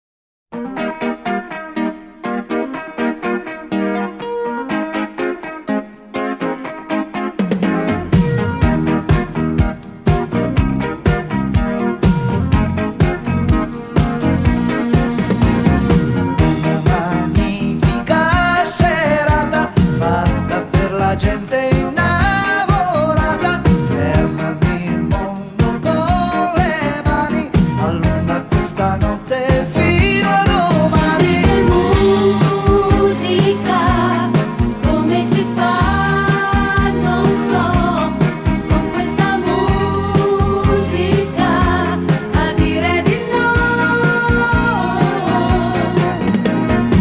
Громкие рингтоны